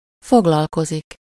Ääntäminen
IPA: [tʁe.te]